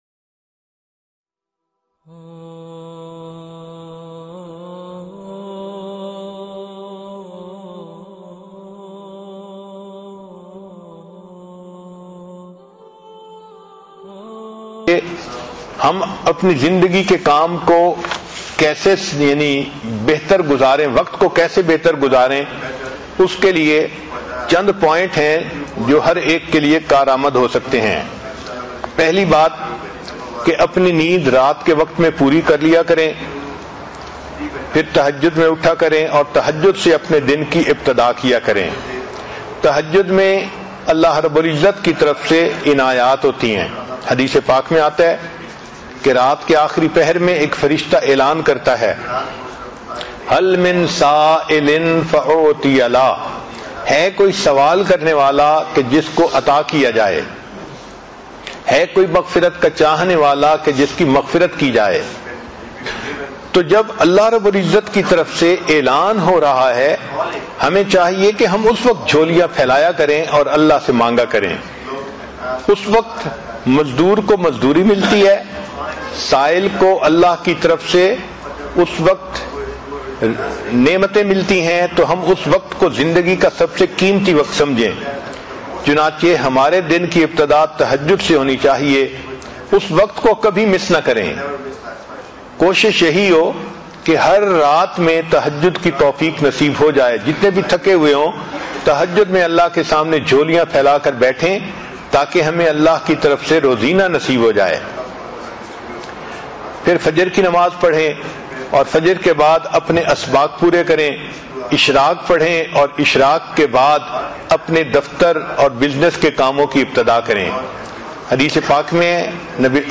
3 Steps for successful life bayan mp3